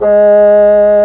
Index of /90_sSampleCDs/Roland LCDP04 Orchestral Winds/WND_Bassoons/WND_Bassoon 4
WND BSSN G#3.wav